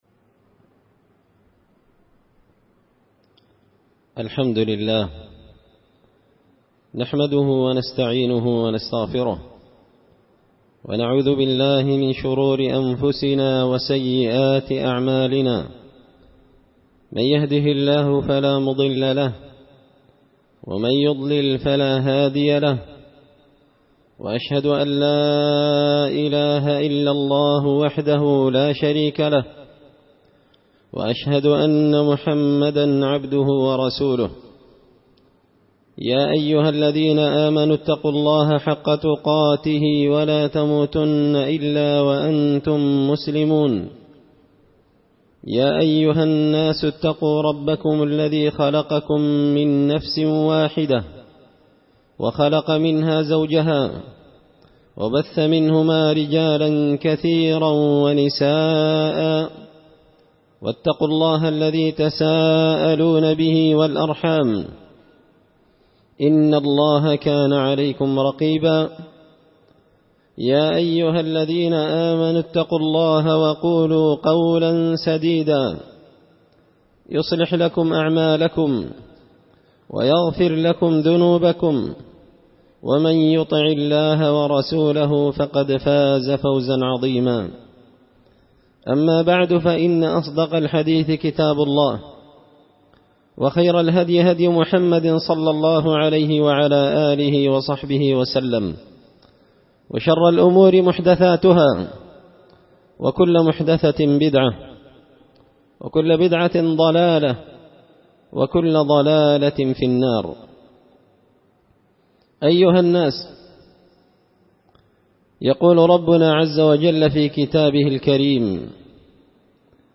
خطبة جمعة بعنوان – من أصبح آمنا في سربه
دار الحديث بمسجد الفرقان ـ قشن ـ المهرة ـ اليمن